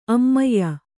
♪ ammayya